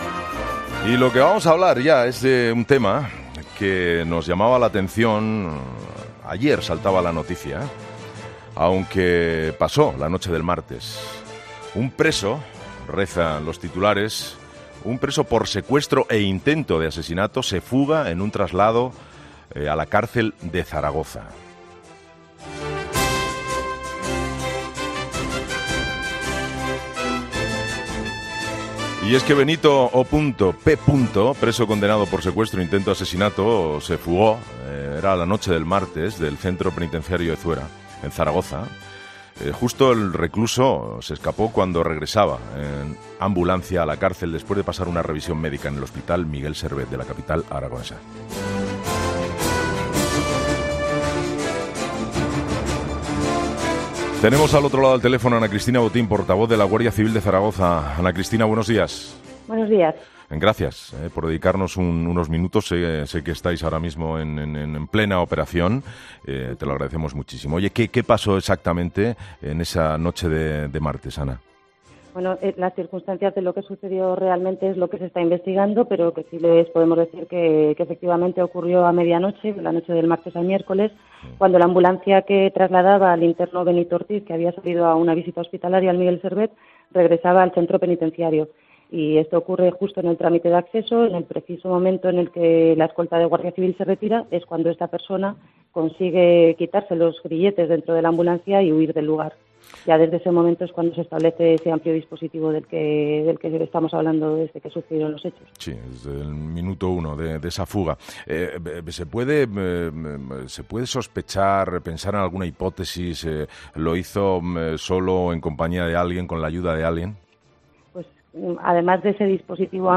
Entrevista a la Guardia Civil sobre el preso fugado de Zaragoza